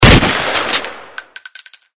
pulja.mp3